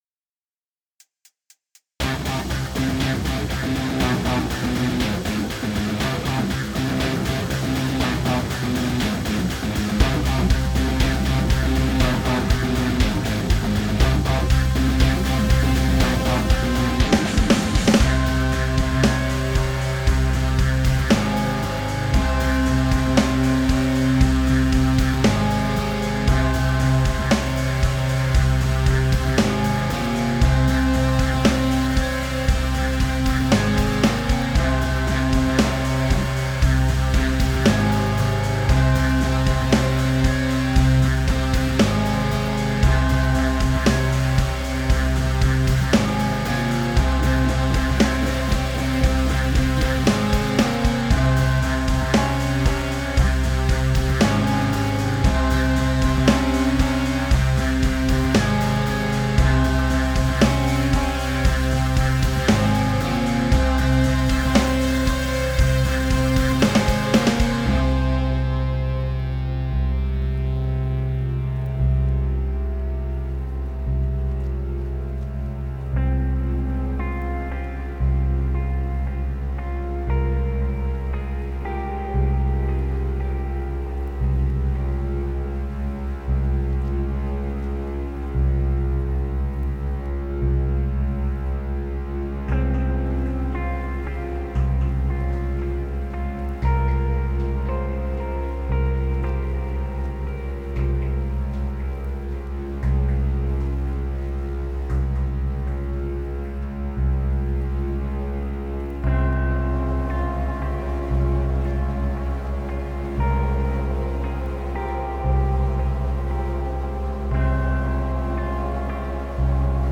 AmbientSoundtrackCinematic